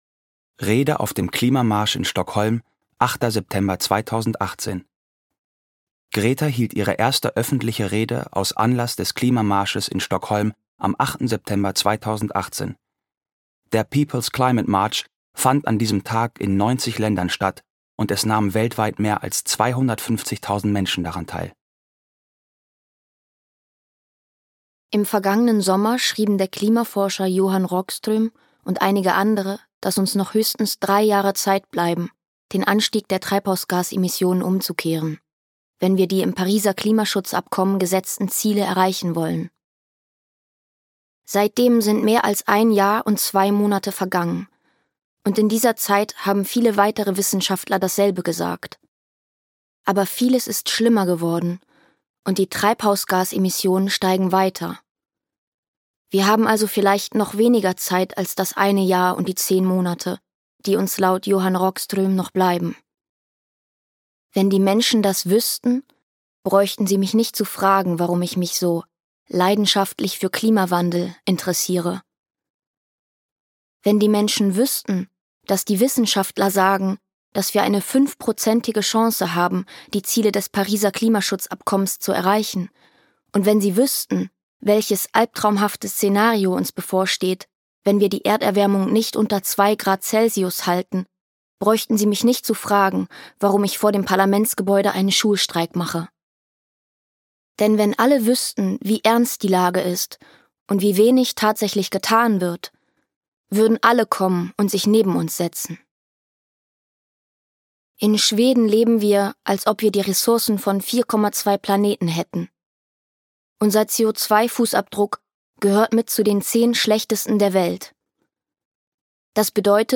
Ich will, dass ihr in Panik geratet! - Greta Thunberg - Hörbuch